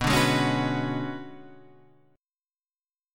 Bm6add9 chord {x 2 0 1 2 2} chord